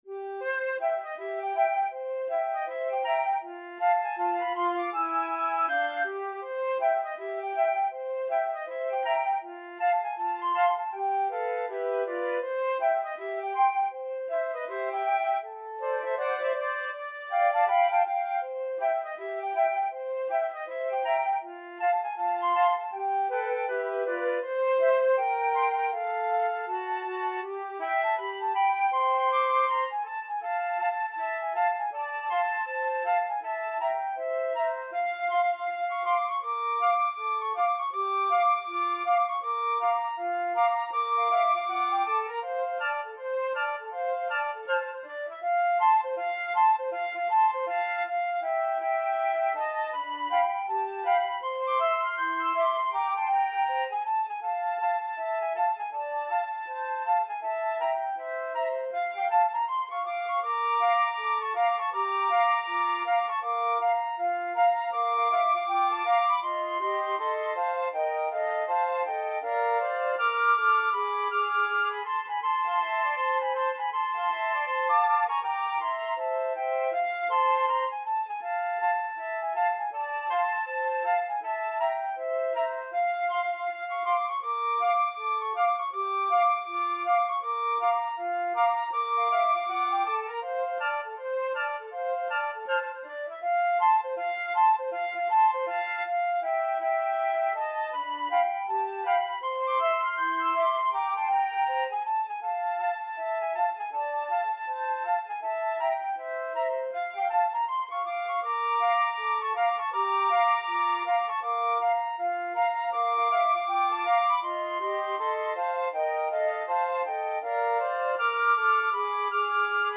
4/4 (View more 4/4 Music)
Flute Trio  (View more Intermediate Flute Trio Music)
Jazz (View more Jazz Flute Trio Music)